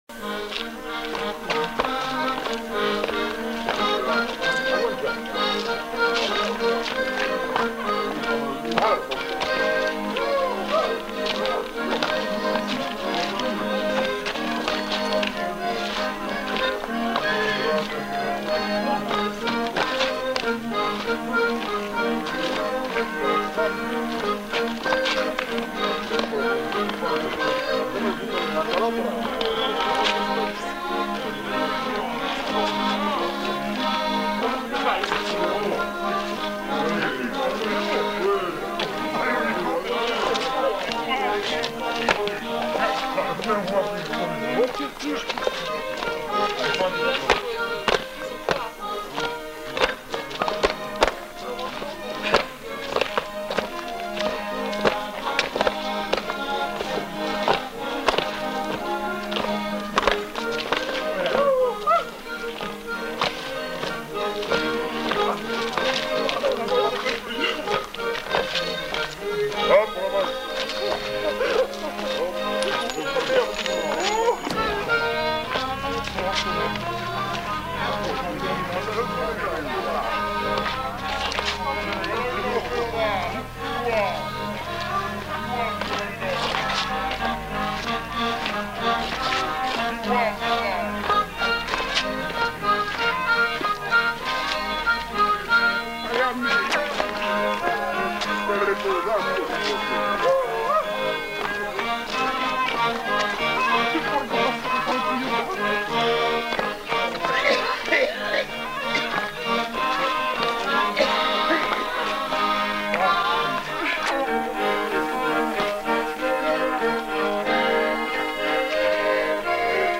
Aire culturelle : Savès
Lieu : Espaon
Genre : morceau instrumental
Instrument de musique : accordéon diatonique ; violon
Danse : rondeau